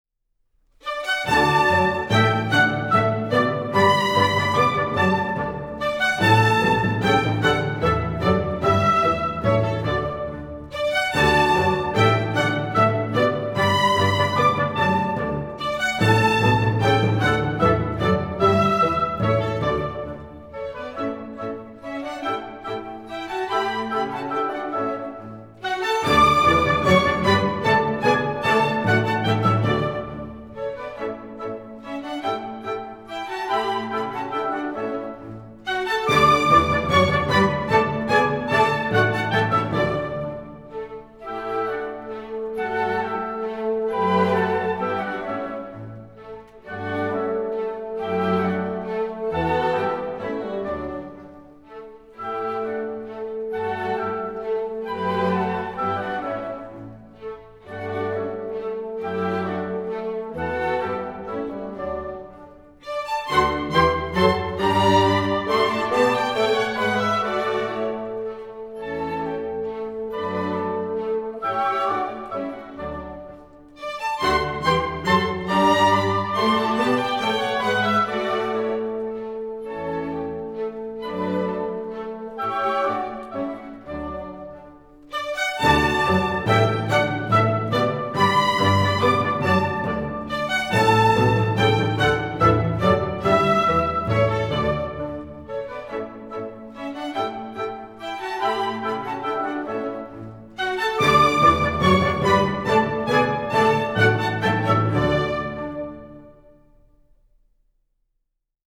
Orquesta
Música clásica